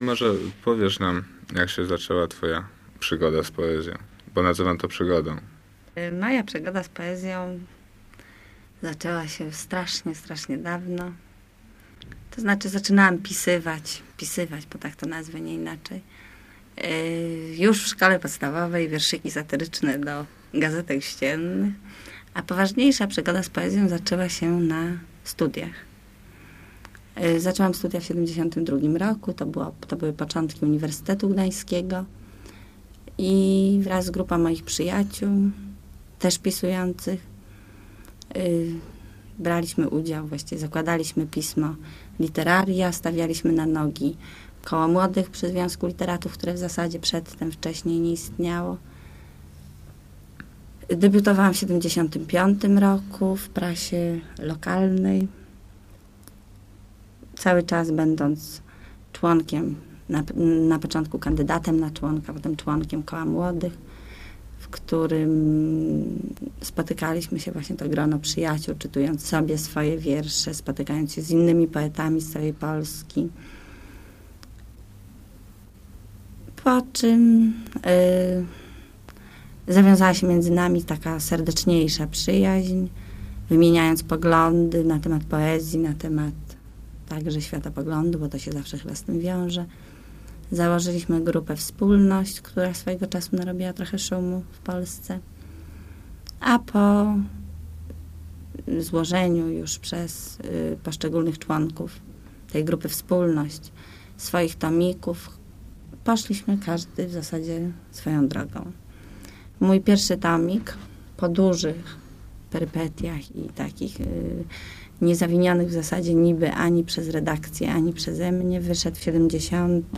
Wywiad
w studiu SAR